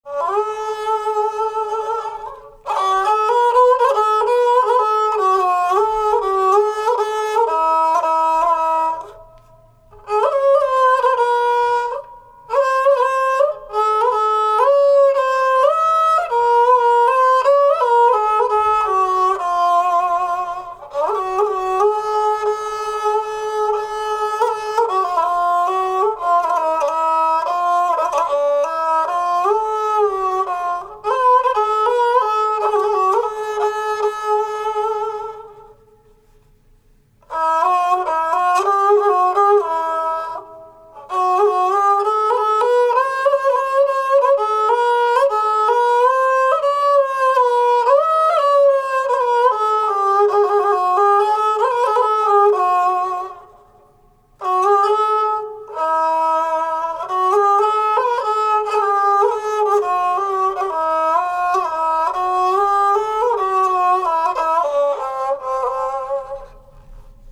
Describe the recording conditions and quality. None of the pieces were rehearsed, all are first takes.